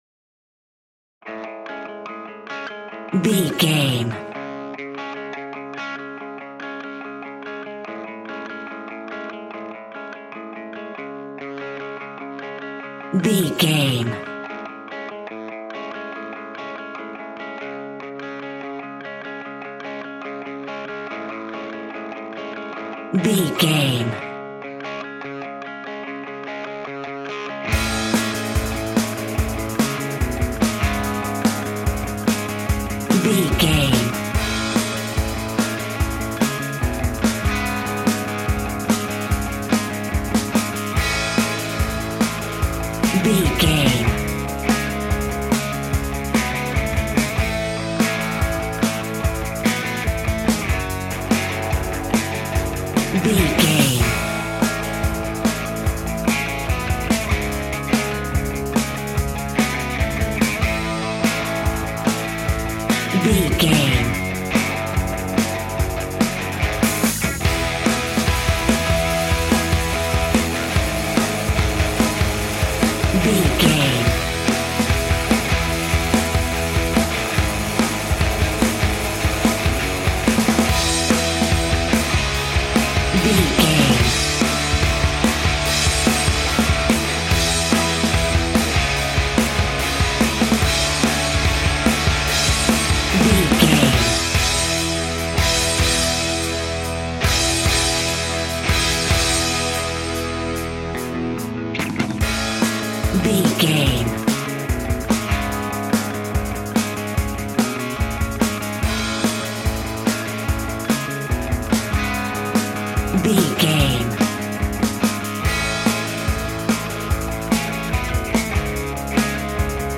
Epic / Action
Fast paced
Ionian/Major
hard rock
heavy metal
rock instrumentals
Heavy Metal Guitars
Metal Drums
Heavy Bass Guitars